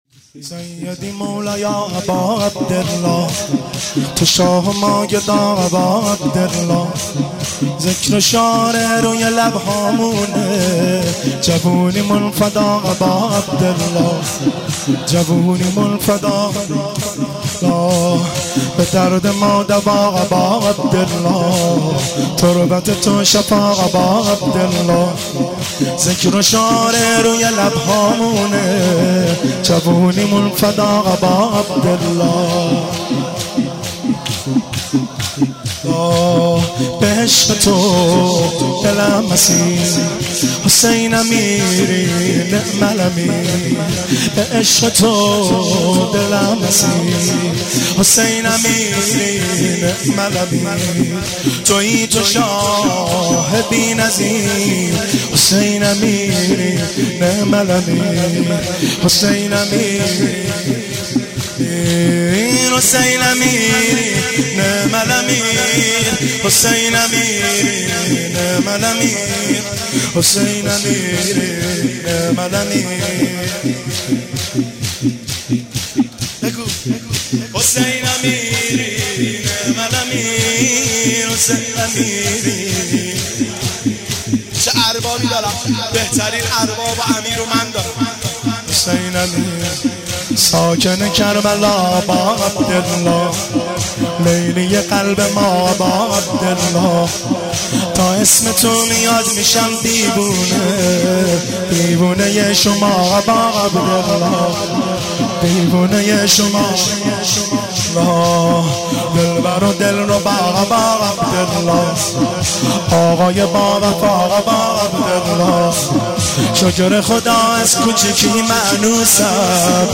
مداحی 1397